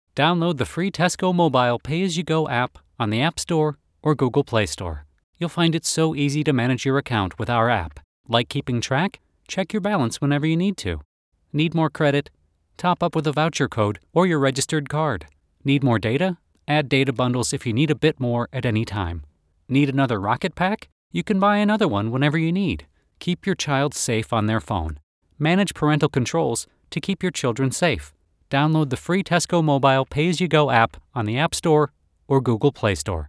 Englisch (Amerikanisch)
Kommerziell, Natürlich, Freundlich, Unverwechselbar, Verspielt
Unternehmensvideo